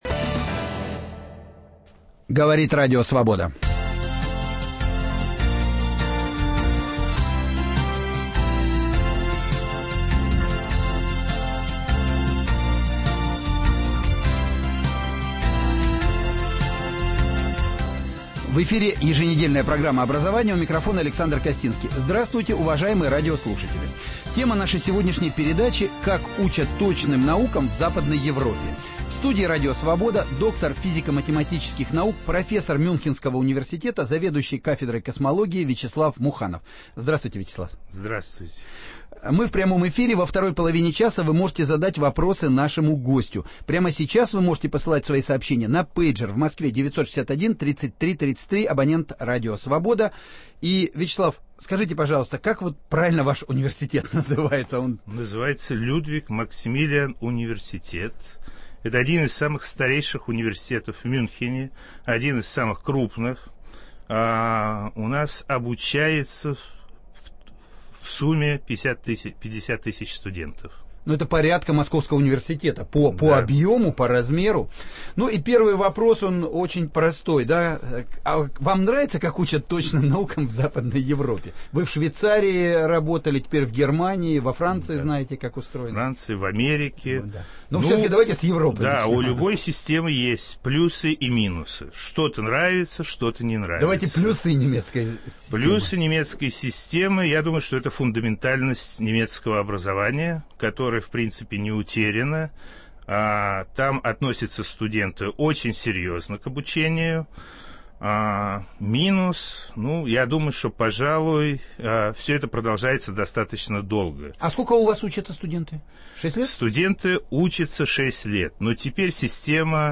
Как учат точным наукам в Западной Европе? В студии радио Свобода: доктор физико-математических наук, профессор Мюнхенского университета, заведующий кафедрой космологии Вячеслав Муханов